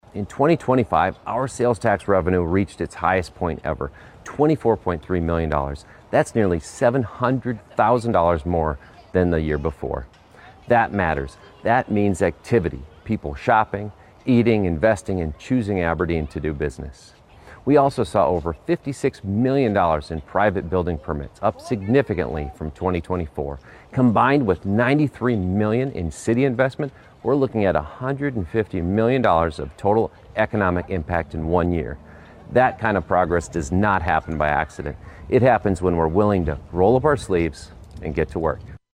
The address was delivered at Storybook Land.